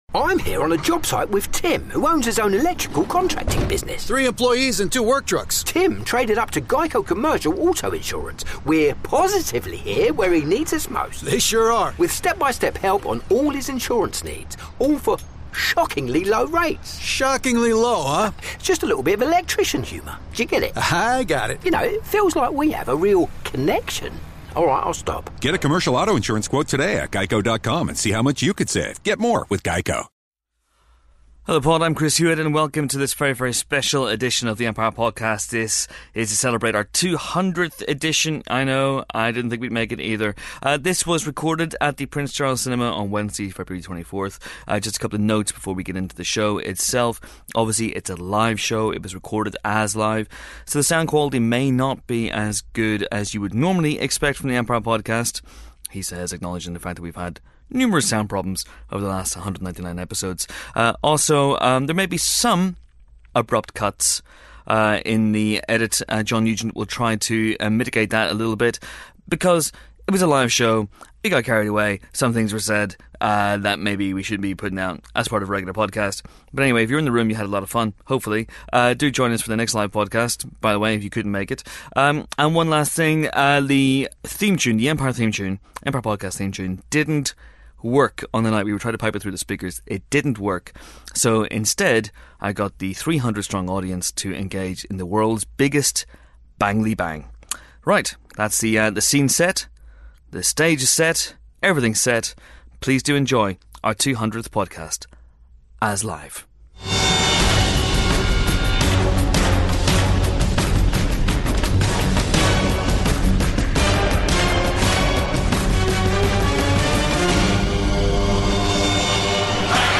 #200: LIVE with Jason Isaacs and Ben Wheatley